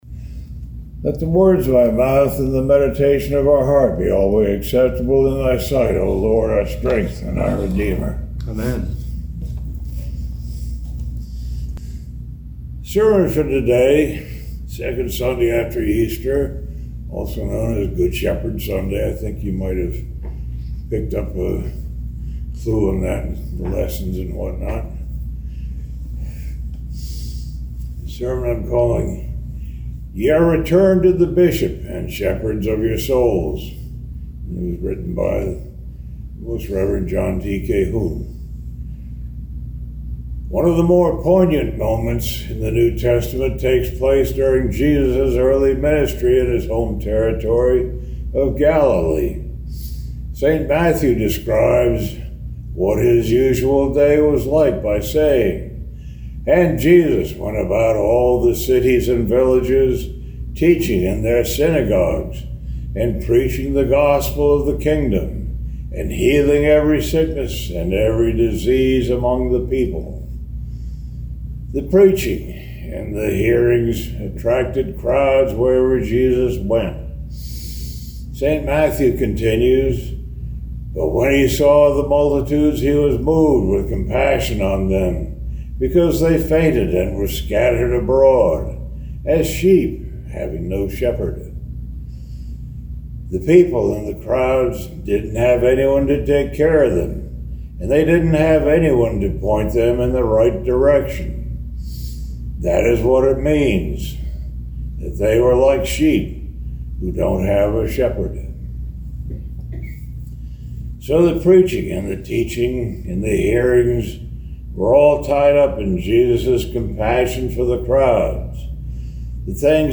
Morning Prayer - Lay Reader Service
Sermon Thought: